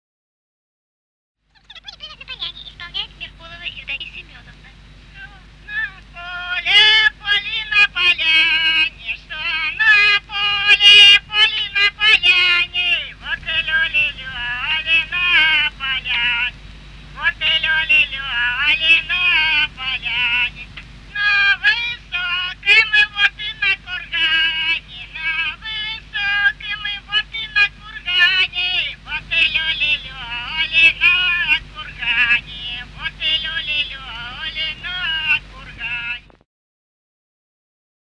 Русские народные песни Владимирской области [[Описание файла::22. Что на поле, поле, на поляне (свадебная) с. Коровники Суздальского района Владимирской области.